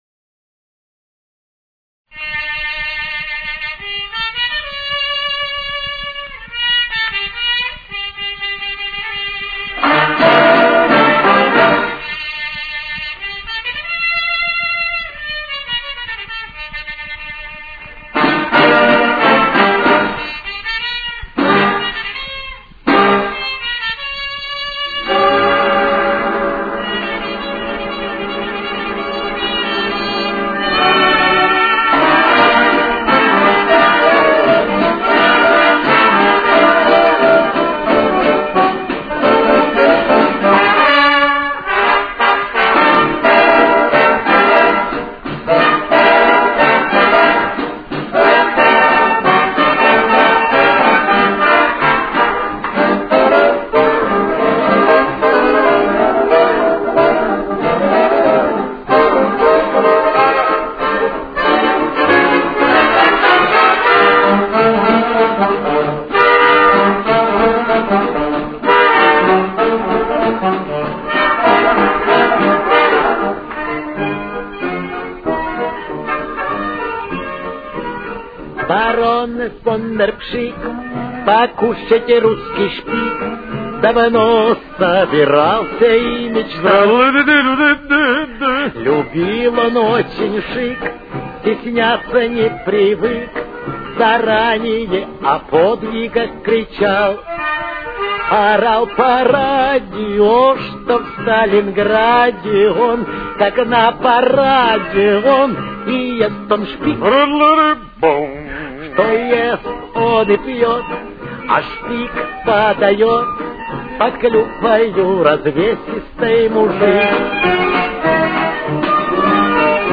Темп: 200.